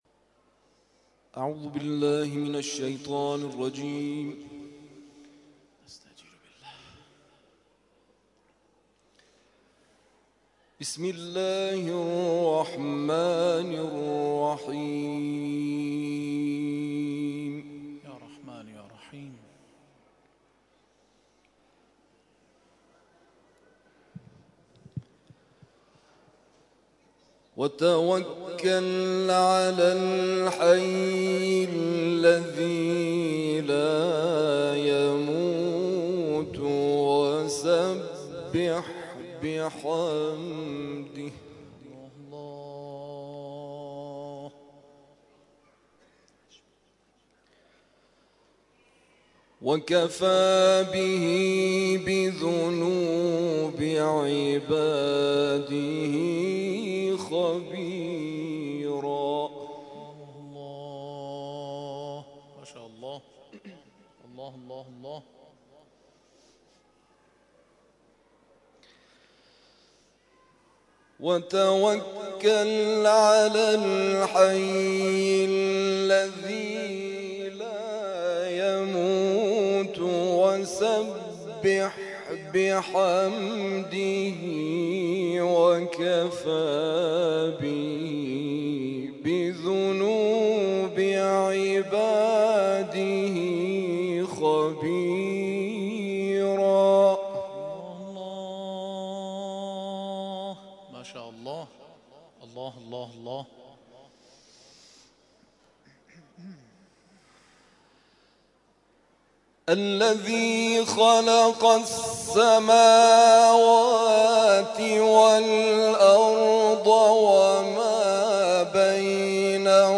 گروه جلسات و محافل: محفل انس با قرآن کریم این هفته آستان عبدالعظیم الحسنی(ع) با تلاوت قاریان ممتاز و بین‌المللی کشورمان برگزار شد.